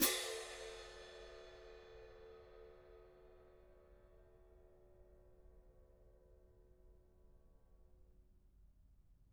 cymbal-crash1_pp_rr1.wav